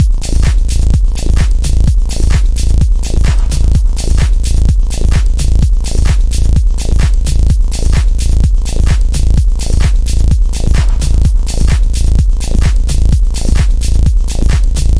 Drum Loops / sequences " 科技恍惚的节奏
Tag: 电子 狂野 房子 很小 高科技 电子乐 舞蹈 精神恍惚 节拍 渐进的 俱乐部